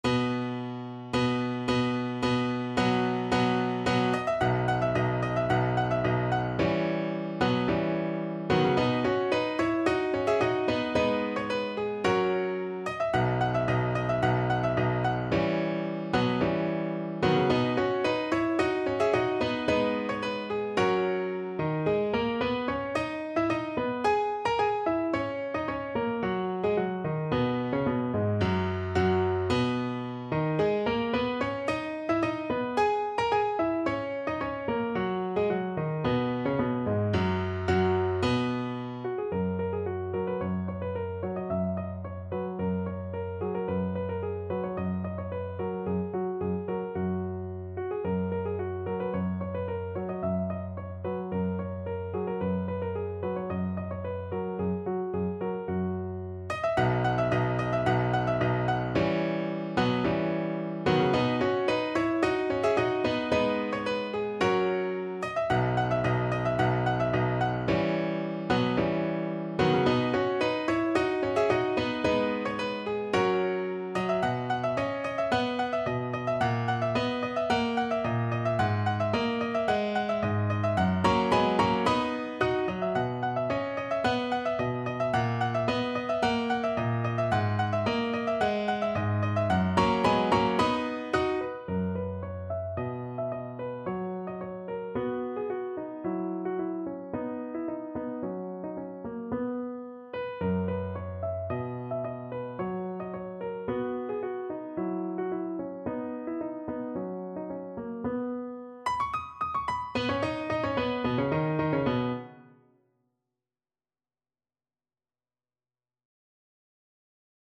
Play (or use space bar on your keyboard) Pause Music Playalong - Piano Accompaniment Playalong Band Accompaniment not yet available transpose reset tempo print settings full screen
B major (Sounding Pitch) (View more B major Music for Violin )
= 110 Allegro di molto (View more music marked Allegro)
2/2 (View more 2/2 Music)
Classical (View more Classical Violin Music)